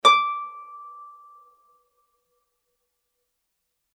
harp
Added sound samples